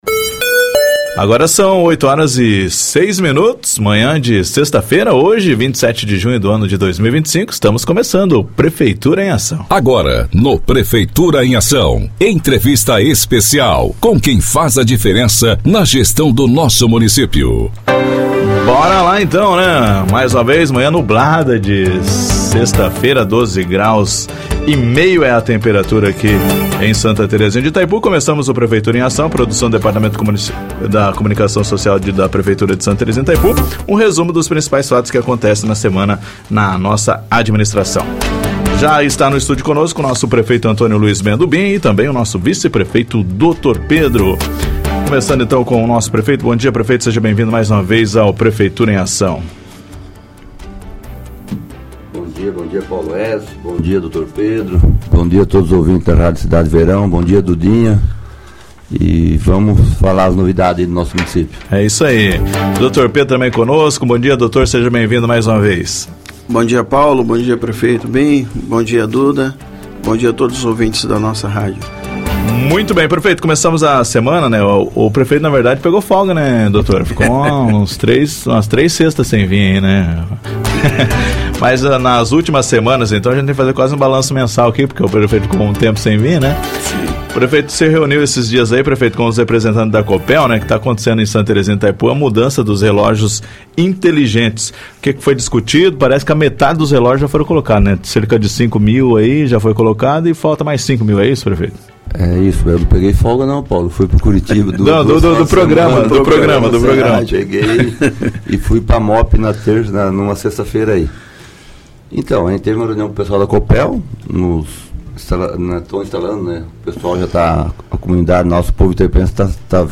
Prefeitura em ação 27/06/2025 ouça a entrevista completa